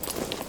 Gear Rustle Redone
tac_gear_35.ogg